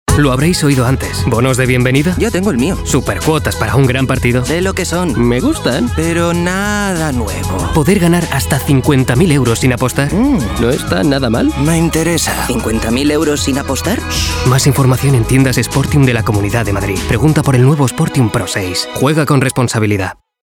TIPO: Campaña publicitaria – Radio.
ESTUDIO: LaHuella Creative Studios